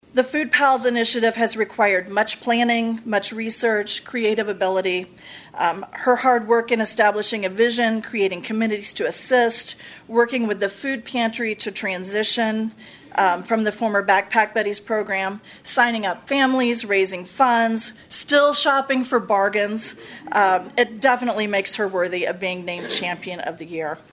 The Champion and Partnership of the Year awards were presented at the Bright Futures Trenton Volunteer Recognition and Celebration Luncheon on October 27.